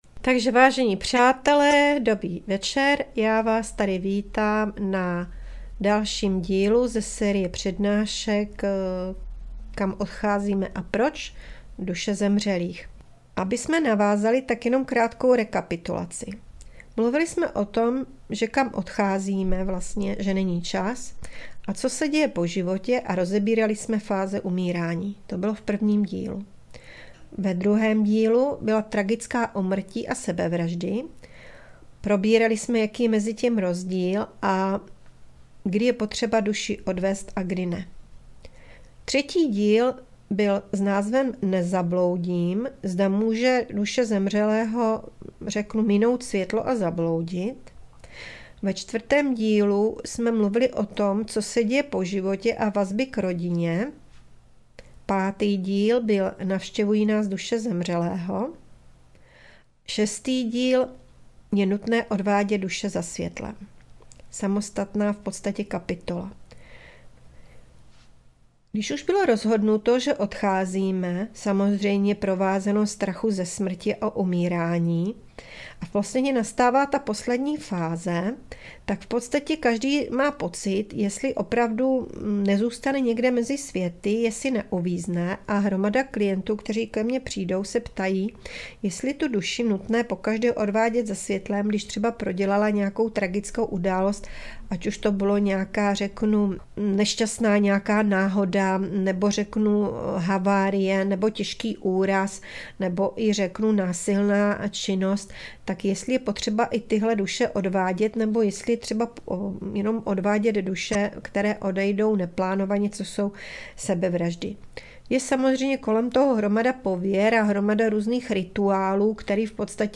Přednáška Duše zemřelých, díl 6. - Je nutné odvádět duše za světlem?